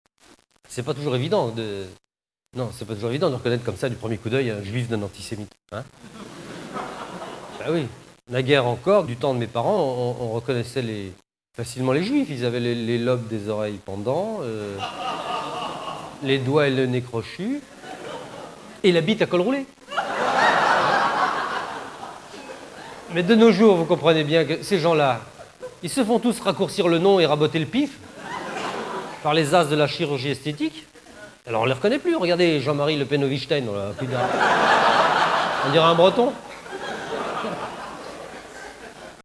« Que choisir ? » Théâtre Grévin